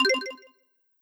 Success6.wav